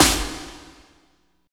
51.01 SNR.wav